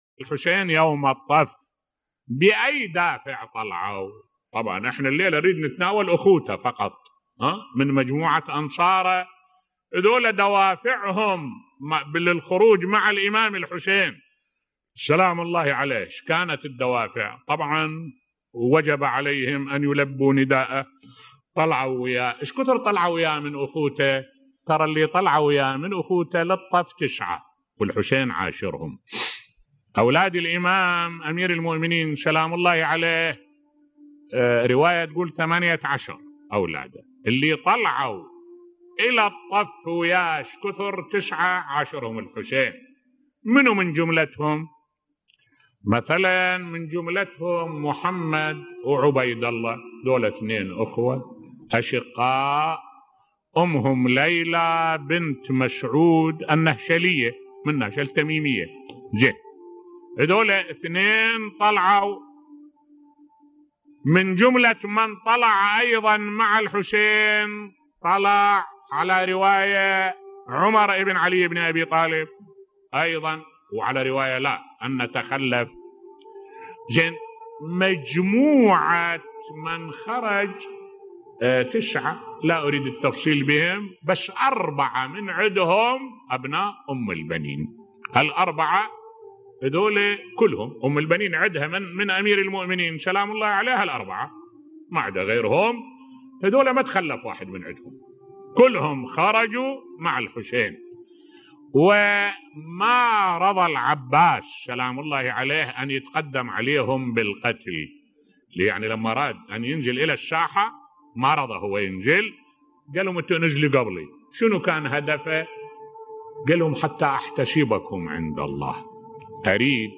ملف صوتی قصة استشهاد ابي الفضل العباس بصوت الشيخ الدكتور أحمد الوائلي